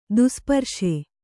♪ dusparśe